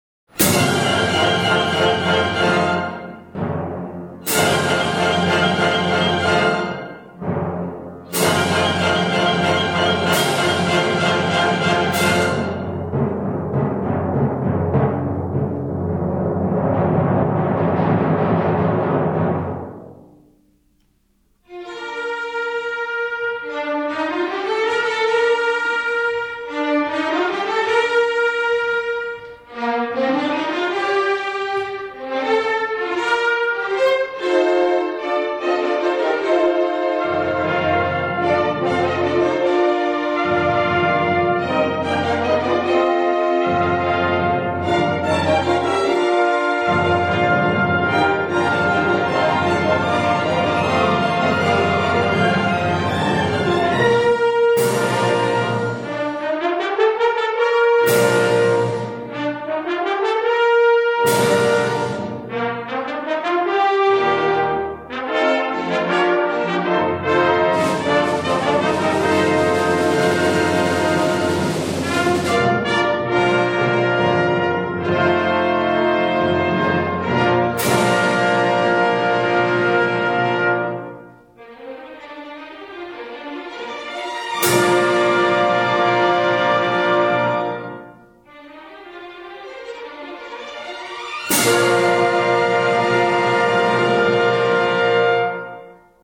Enregistrement live.
soprano
ténor